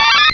pichu.aif